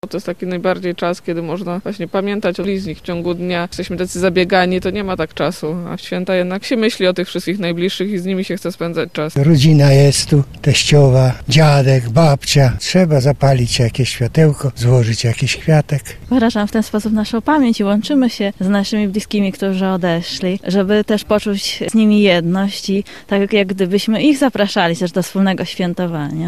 – To nasz obowiązek, wyraz pamięci o bliskich i zaproszenie ich do stołu – mówią odwiedzający cmentarz przy ul. Lipowej w Lublinie.